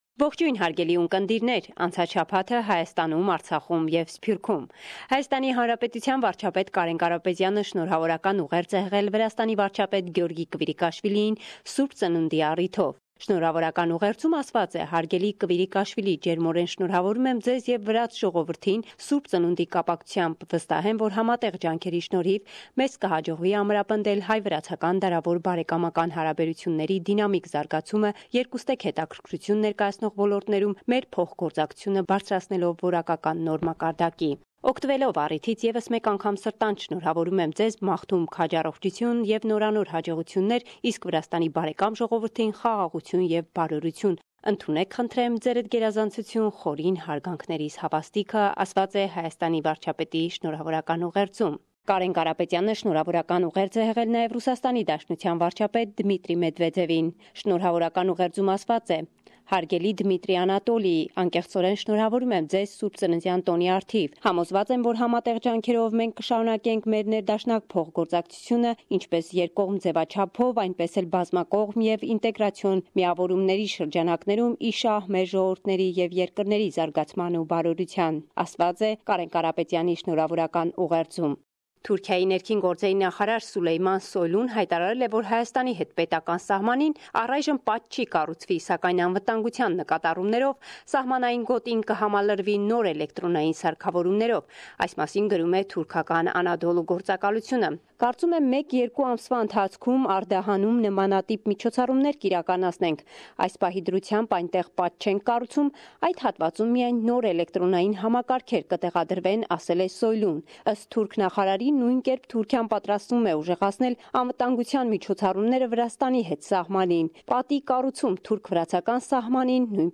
Վերջին լուրերը - 9 Յունուար 2018